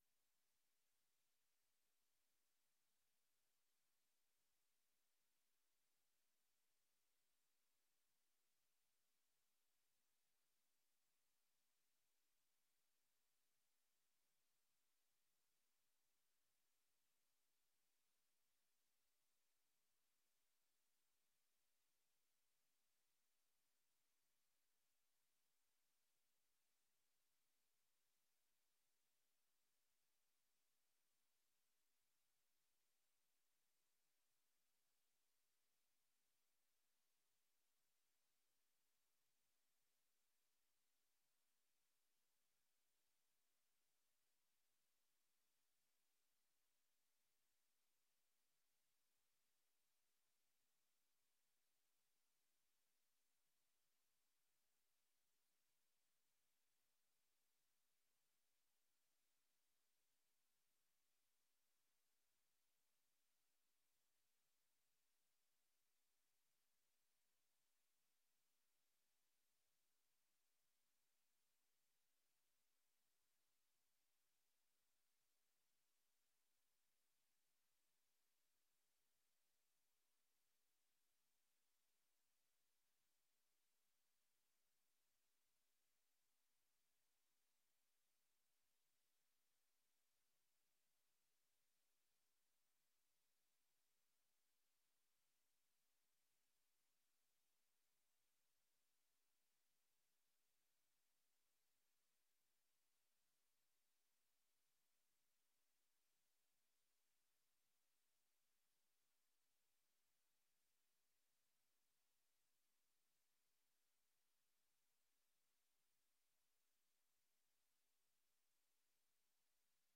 Oordeelsvormende vergadering 10 april 2025 21:00:00, Gemeente Dronten
Download de volledige audio van deze vergadering